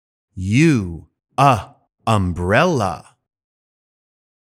音声を聴いて、このゲームの中で使われている単語をフォニックスの読み方で発音してみよう！